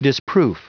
Prononciation du mot disproof en anglais (fichier audio)
Prononciation du mot : disproof